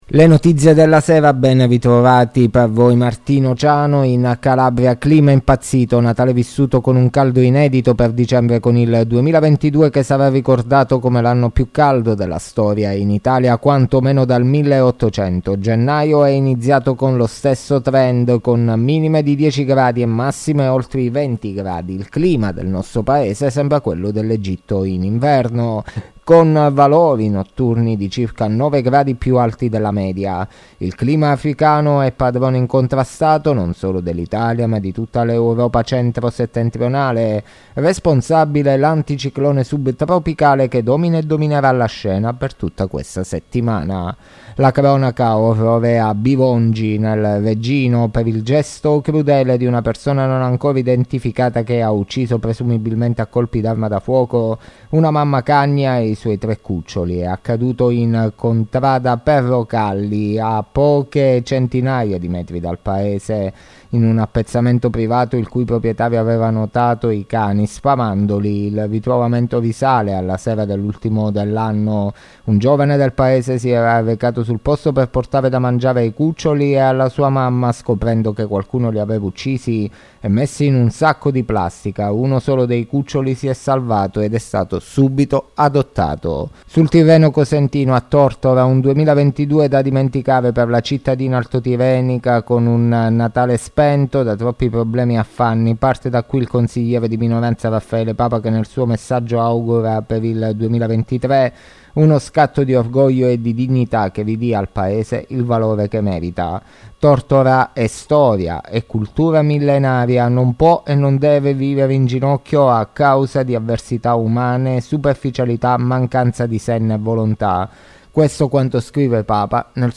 LE NOTIZIE DELLA SERA DI MARTEDì 03 GENNAIO 2023